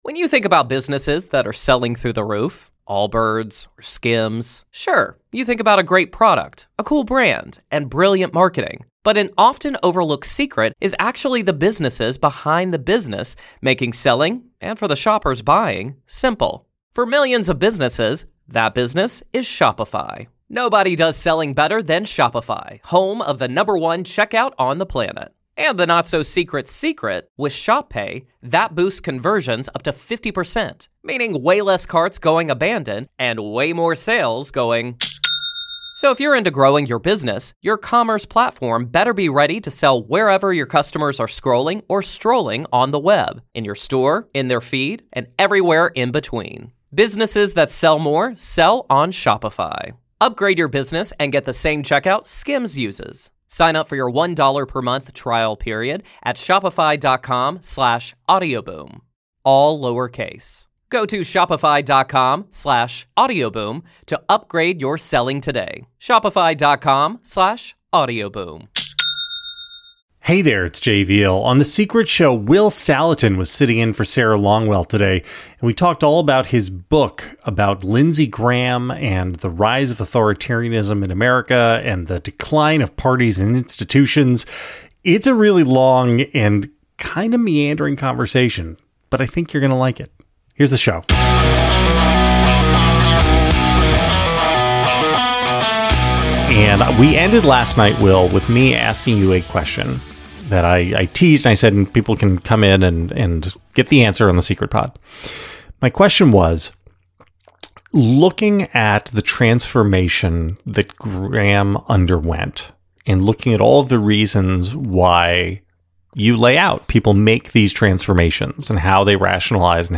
It’s a *much* more free-flowing and philosophical discussion than I’d planned.